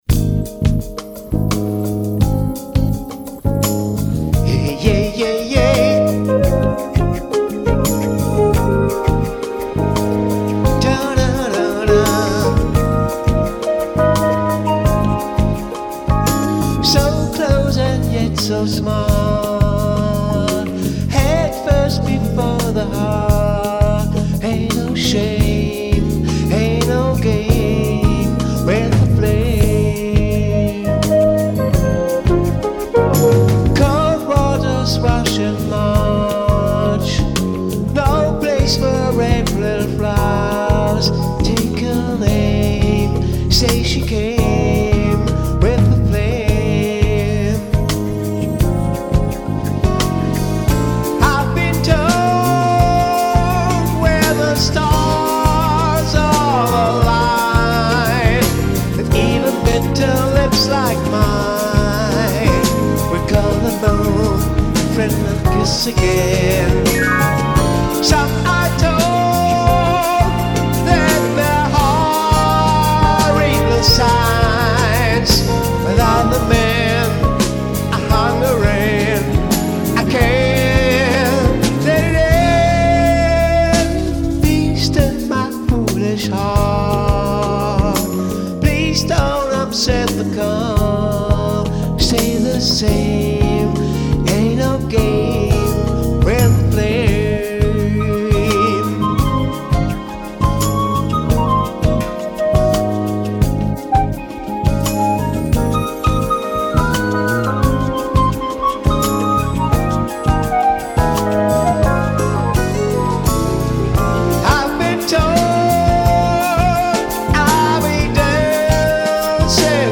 Style: Jazz
Inkl. Solopart unisono zum Synthesizersolo gesungen
Microphone: Beyerdynamic TG V35d s